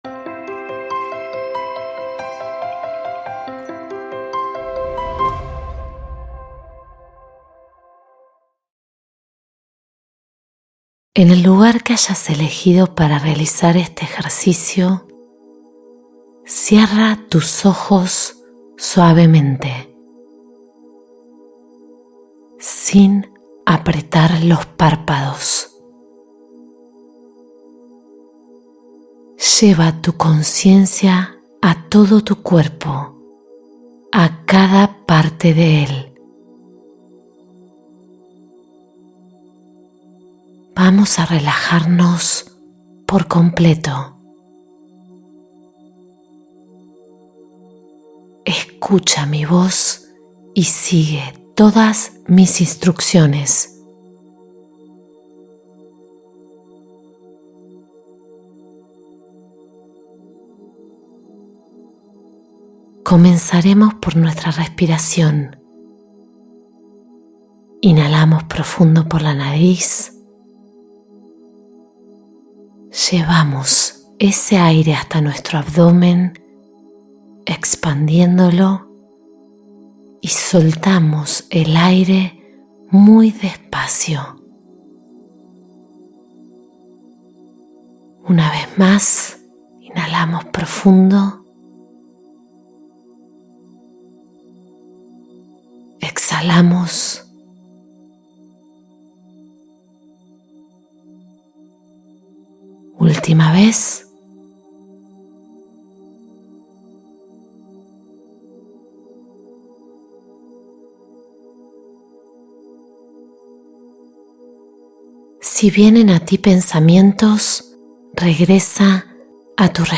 La Gratitud que Abre el Pecho: Meditación para Sentir Calidez Interior